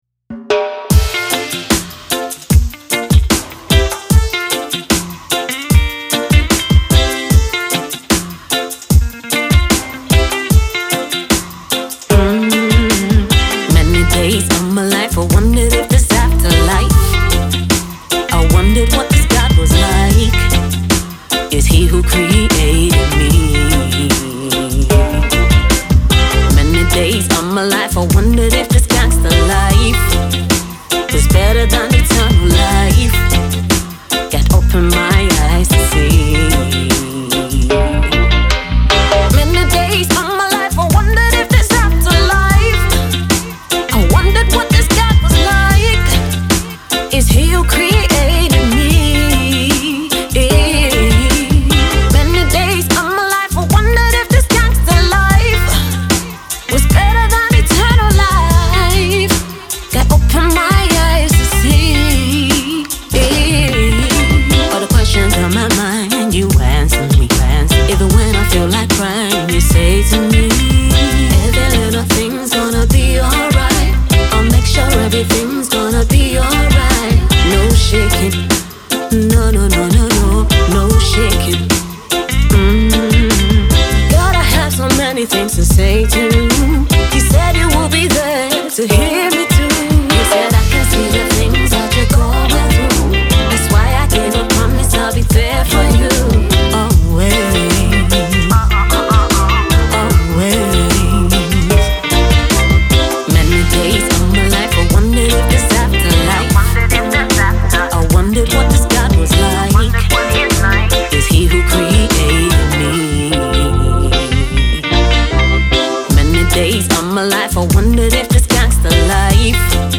an award winning recording Gospel Artist and Songwriter
raggea tune